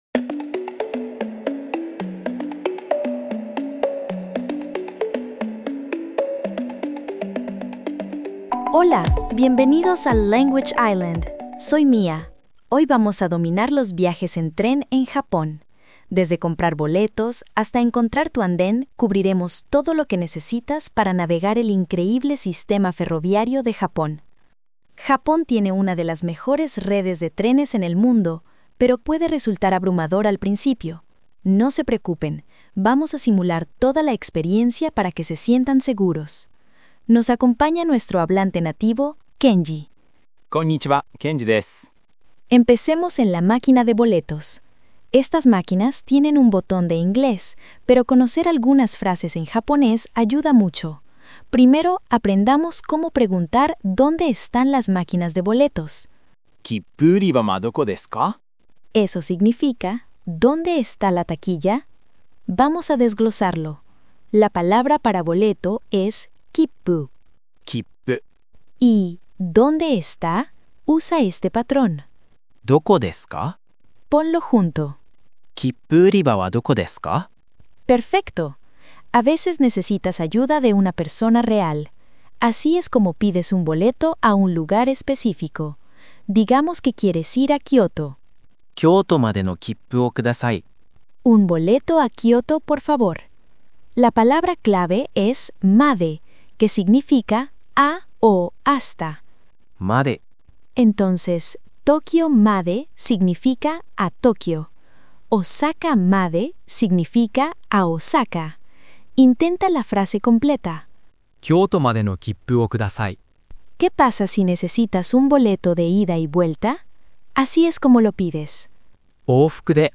Domina los Viajes en Tren en Japón | Japonés para Principiantes